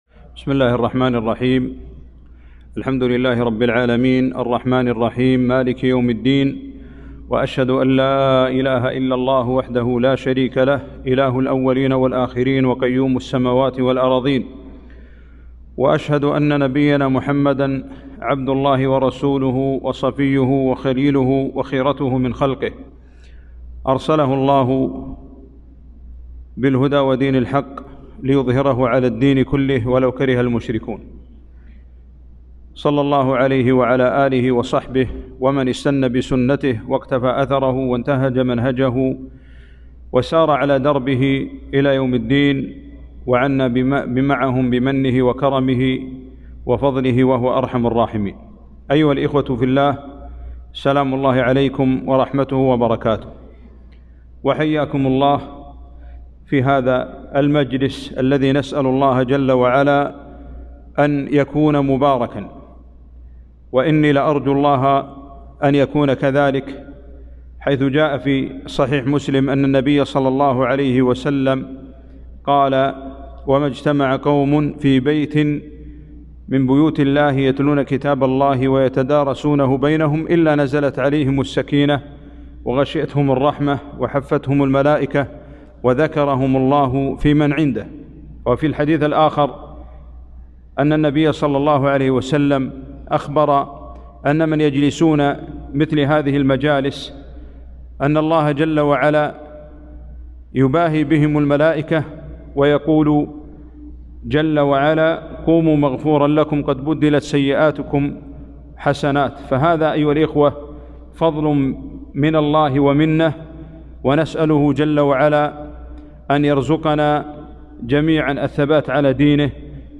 محاضرة - العناية بالقرآن حفظًا وتلاوةً وتدبرا